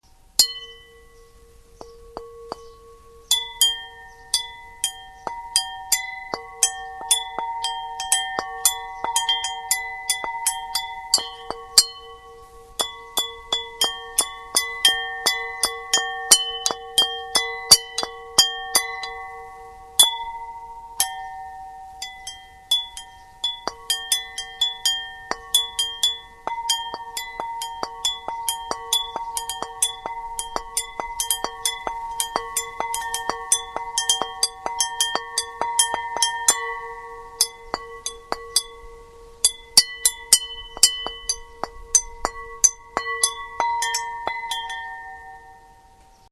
Cloches et gongs
Arbre à cloches